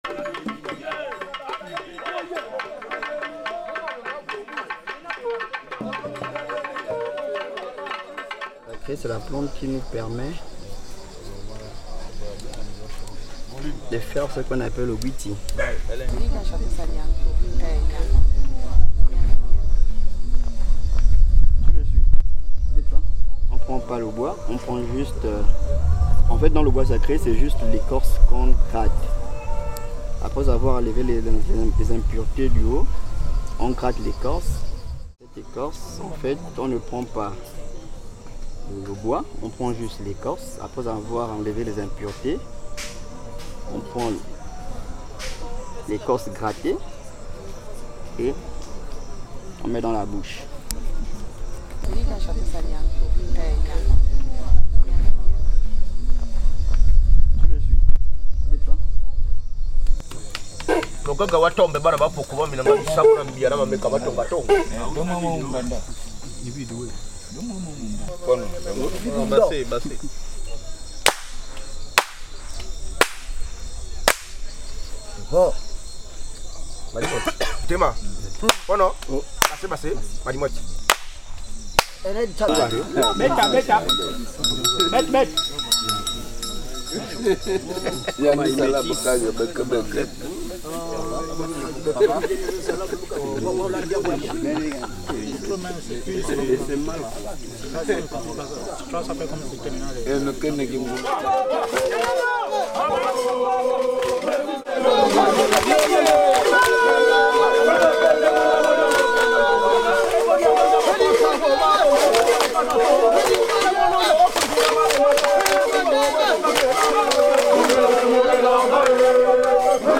Le Mozenguè est la plus grande cérémonie du Bwete dissumba, qui dure 3 jours. Nous avons filmé la dernière nuit. Ordinairement, le Mozenguè est rattaché à un thème joyeux et c'est la plus fastueuse de toutes les cérémonies, une véritable invitation à la réjouissance.